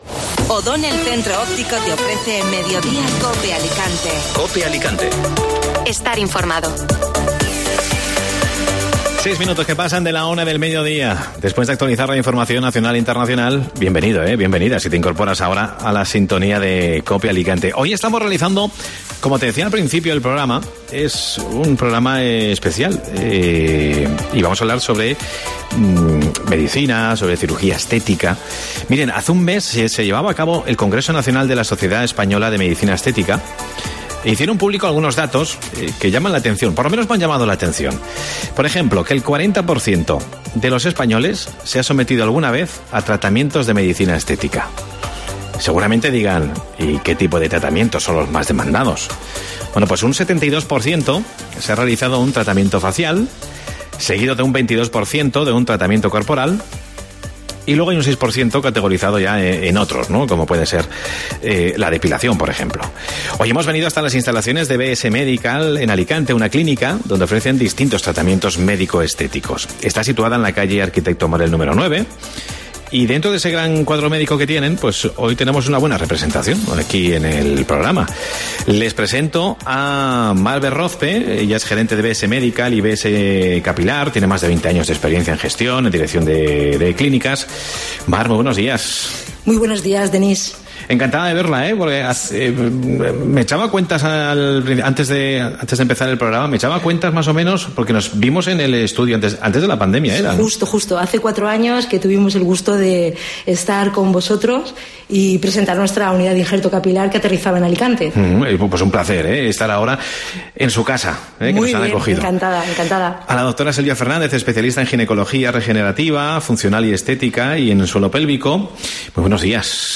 AUDIO: Mediodía COPE Alicante emite en directo desde la clínica BS Medical y BS Capilar, expertos en medicina estética facial y corporal, ginecología y obesidad.